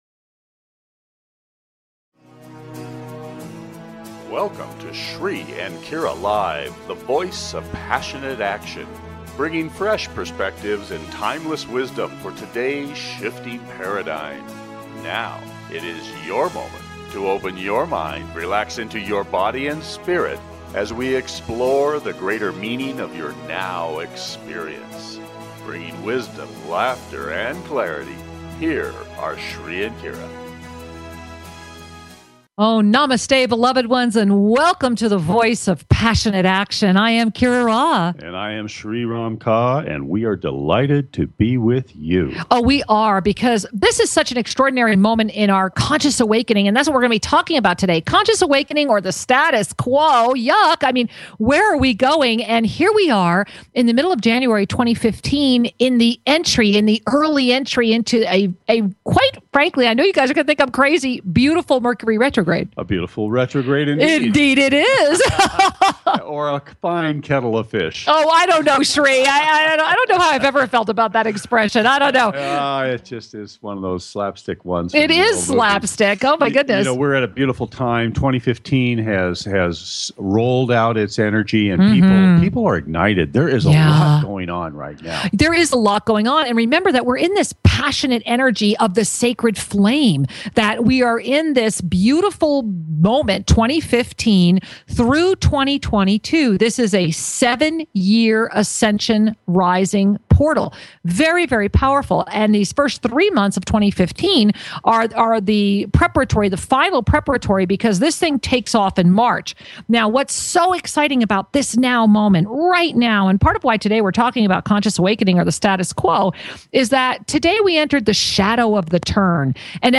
Headlined Show